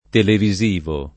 televisivo [ televi @& vo ]